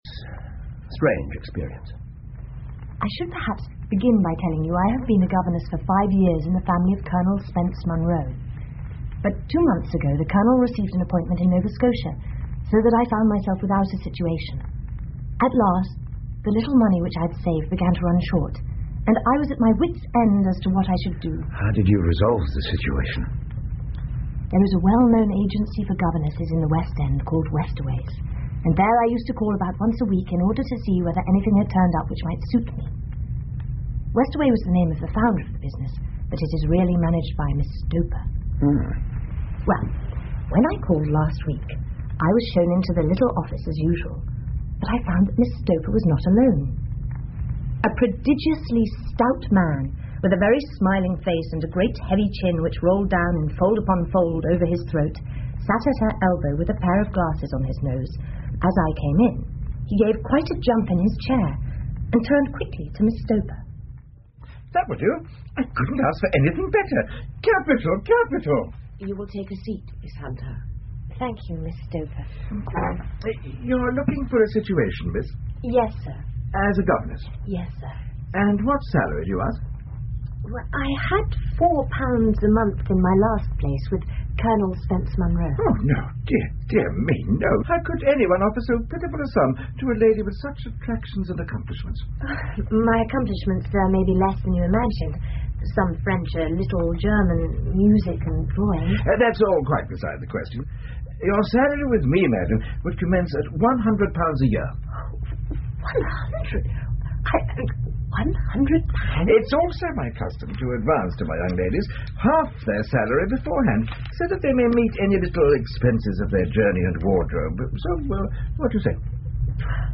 福尔摩斯广播剧 The Copper Beeches 2 听力文件下载—在线英语听力室